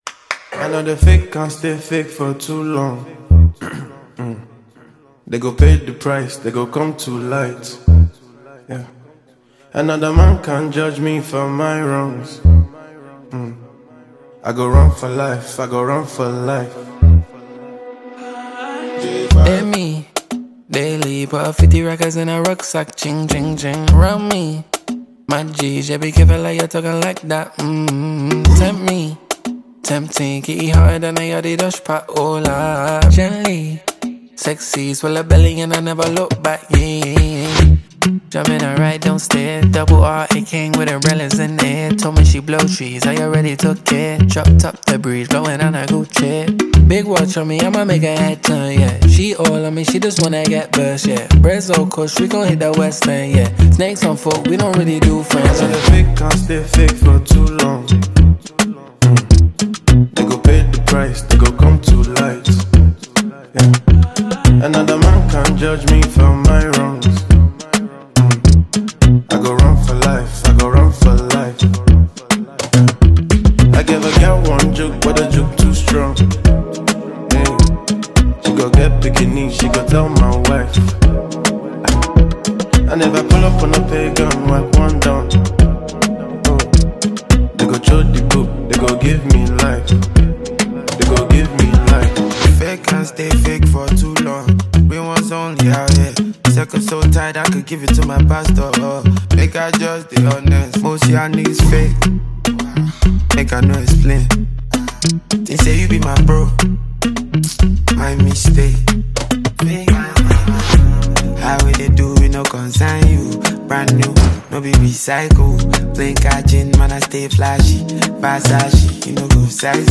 a well-known English hip-hop duo